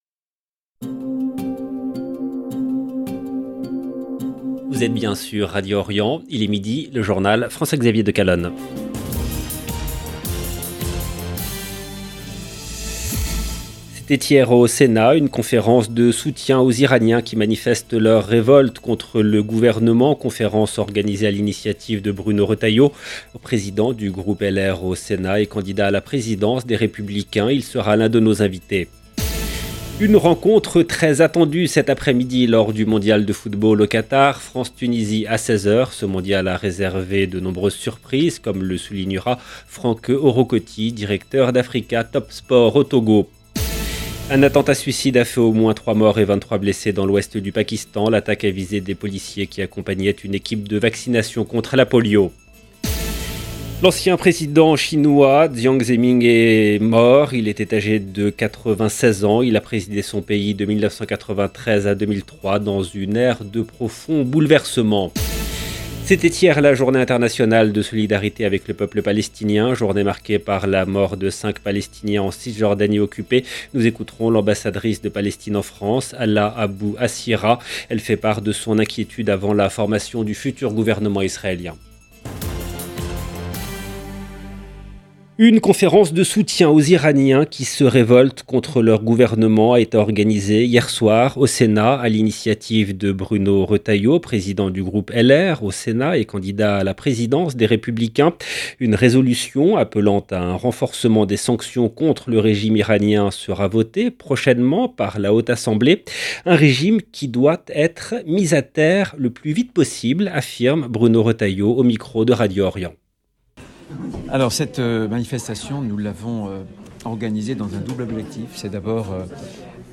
Nous écouterons l’ambassadrice de Palestine en France.